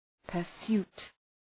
{pər’su:t}
pursuit.mp3